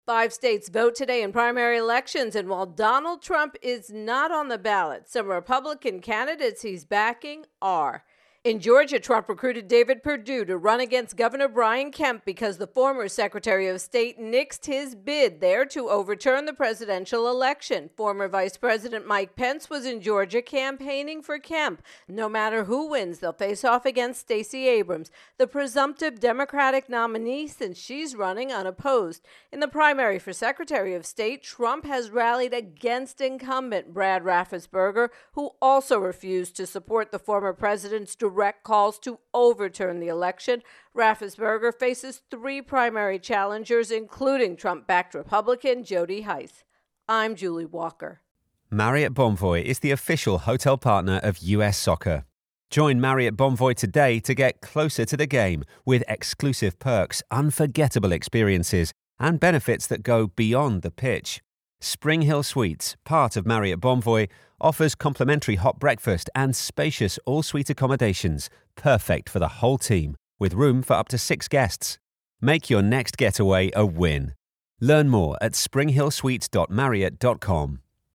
Election 2022 intro and voicer